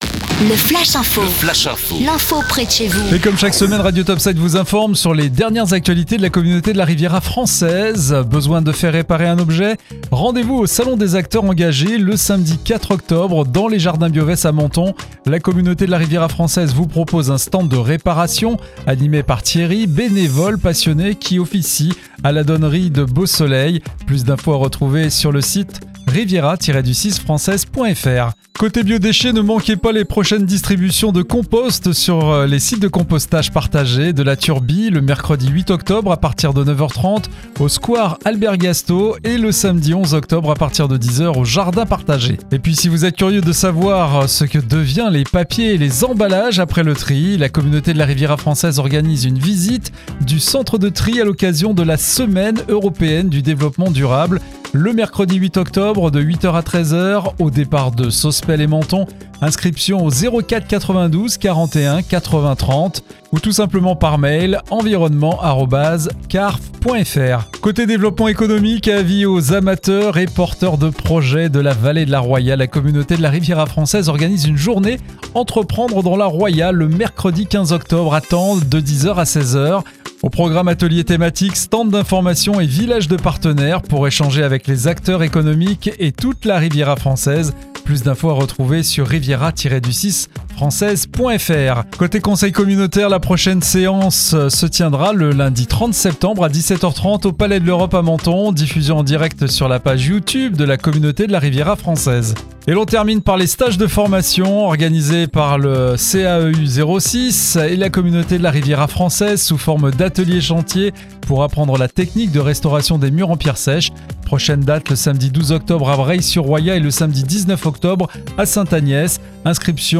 C.A.R.F ACTU - FLASH INFO SEMAINE 39